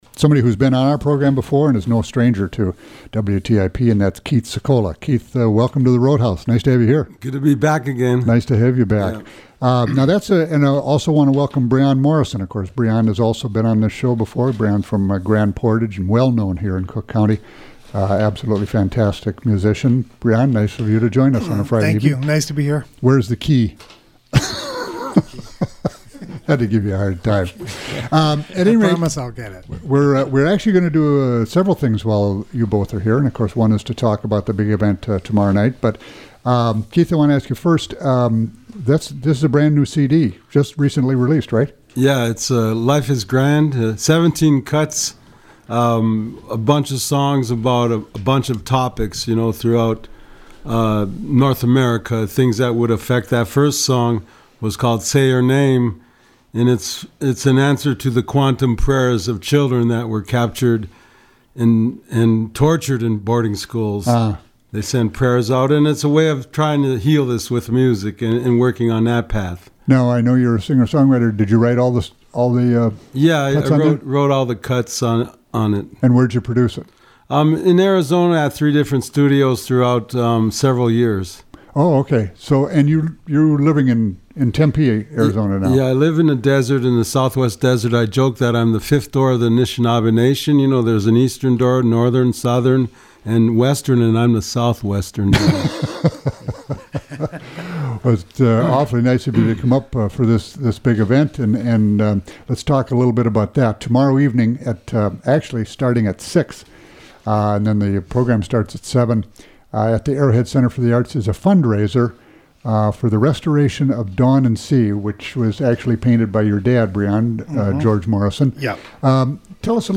Program: Live Music Archive The Roadhouse